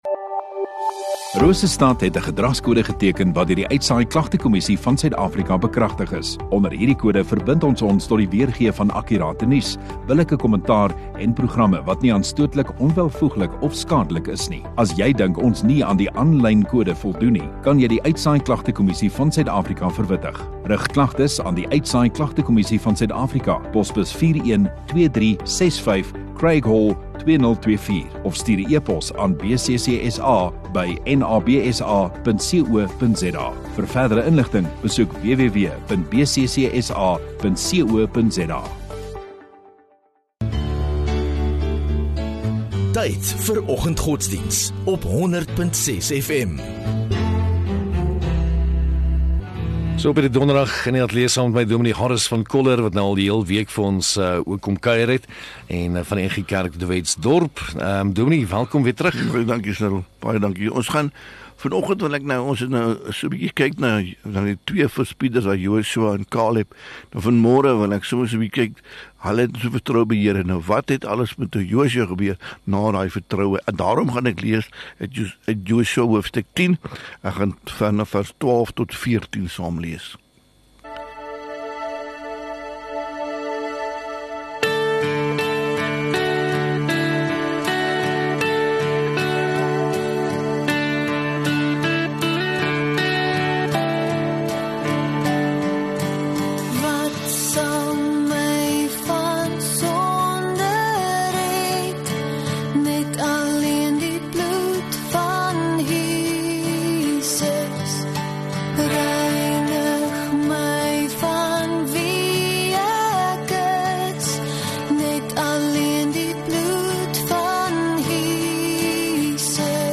26 Oct Donderdag Oggenddiens